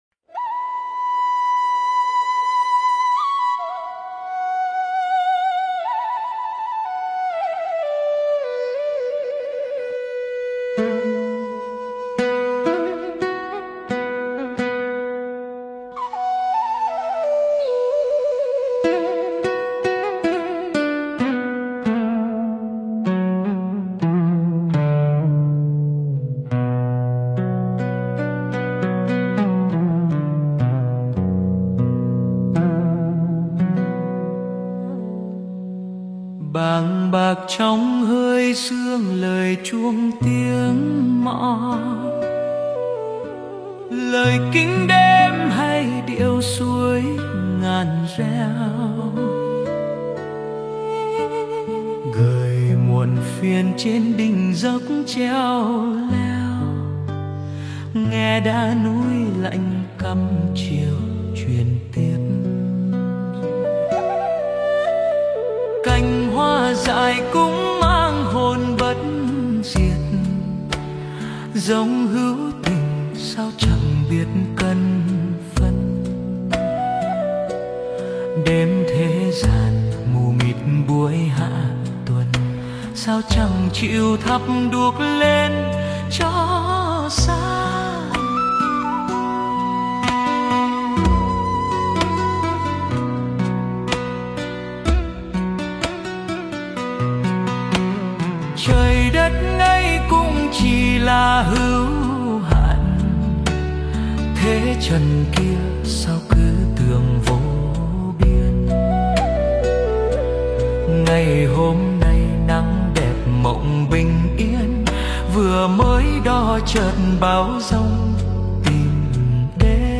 Phổ nhạc: Suno AI